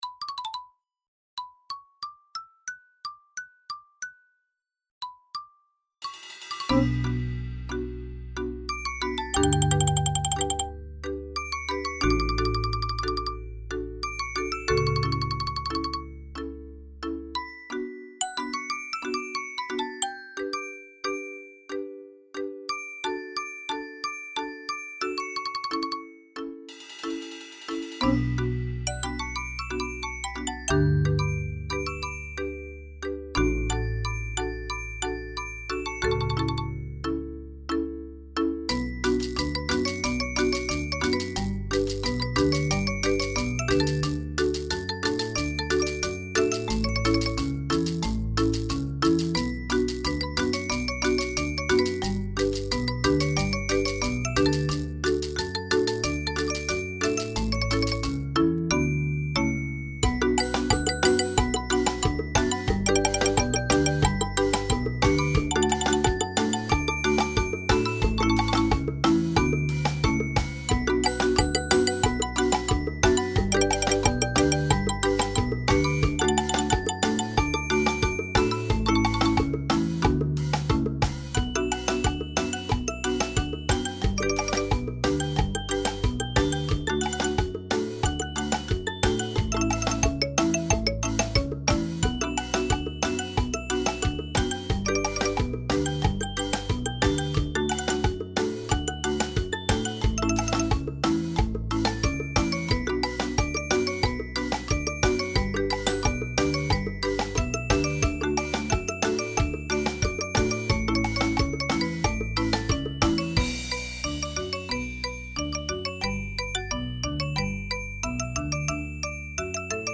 Mallet-Steelband